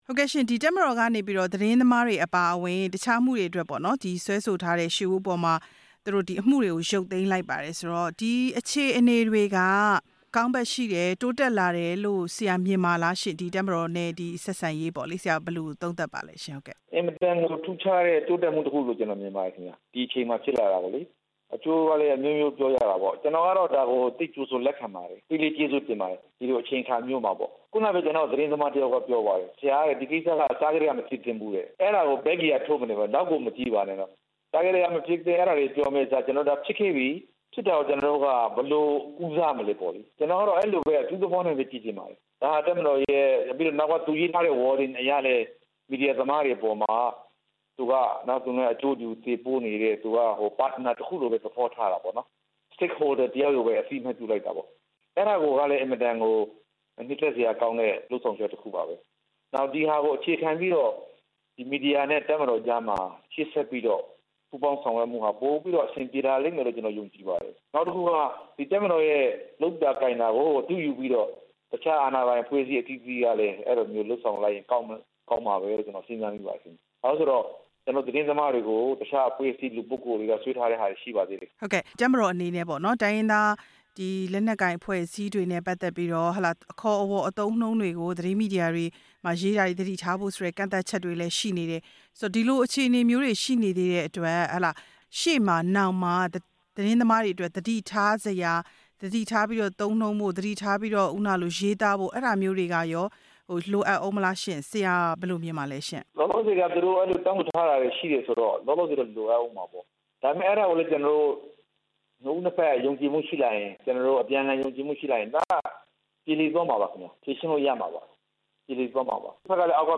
ဆက်သွယ်မေးမြန်းတင်ပြထားပါတယ်။